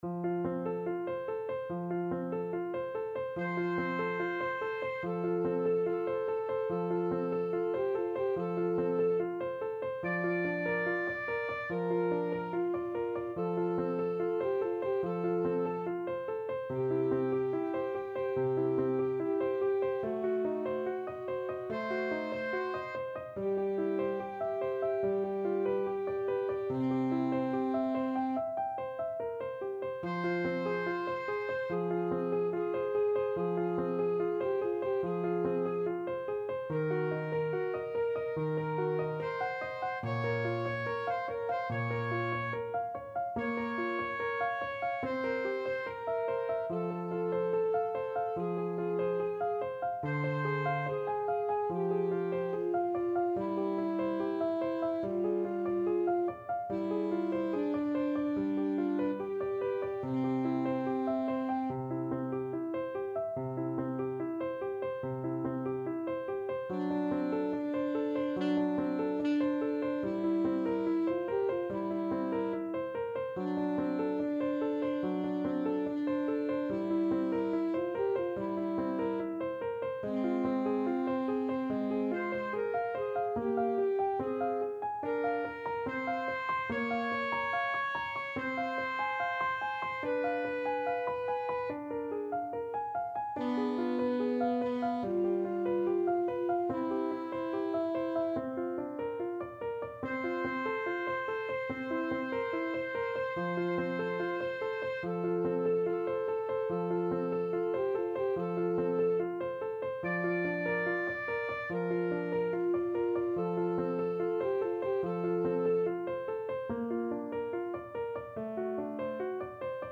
Andante =72
Classical (View more Classical Alto Saxophone Duet Music)